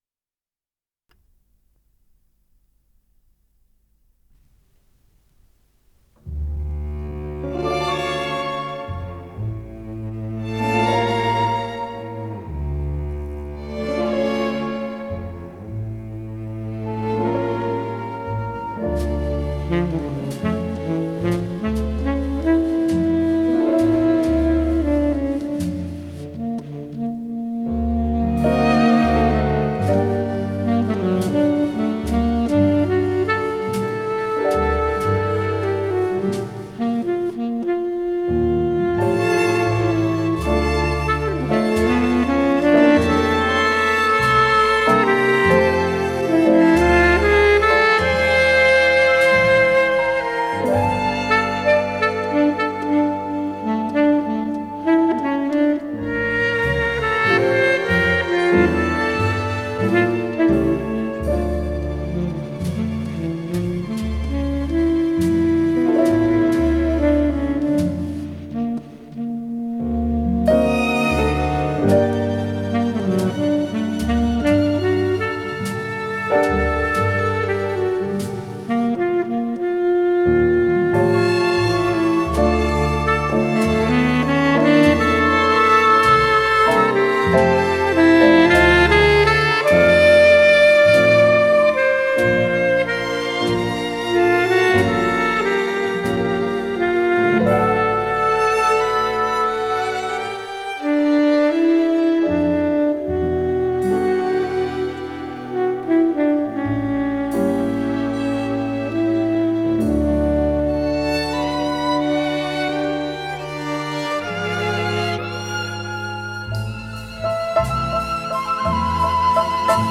с профессиональной магнитной ленты
флейта
тенор-саксофон